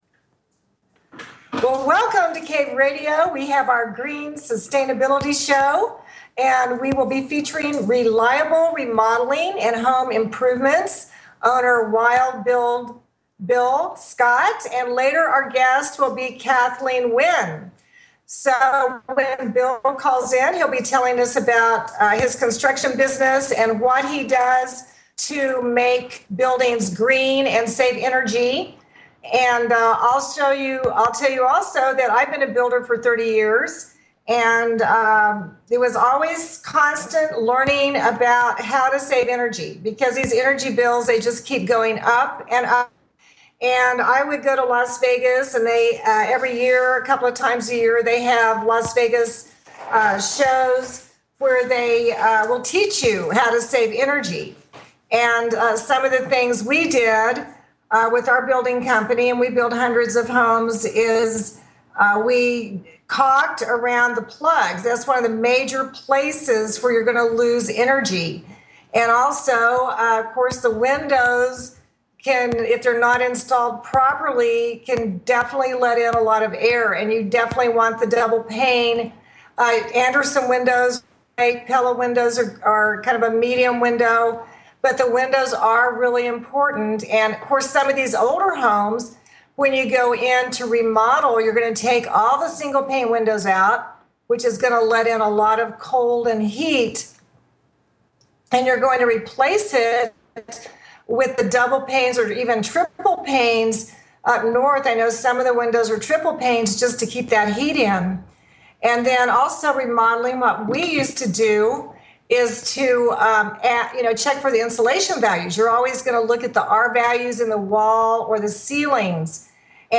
Dr Love Connection Radio Show
There will be many guests on the show including some of our members on the site discussing what they are looking for in a relationship.